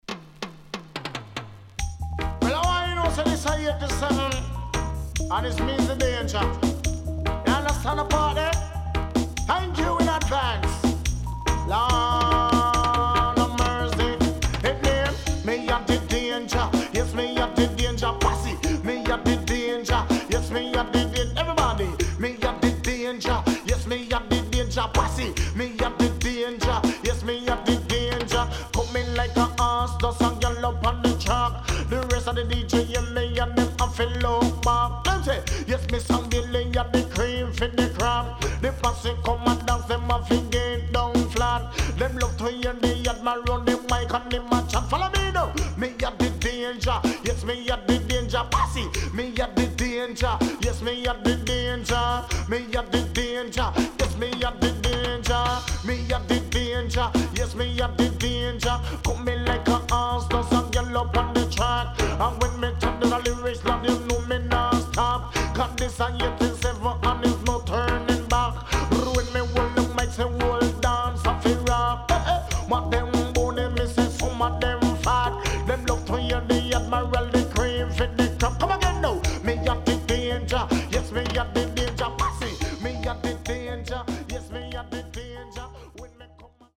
HOME > REISSUE USED [DANCEHALL]
SIDE A:少しチリノイズ入りますが良好です。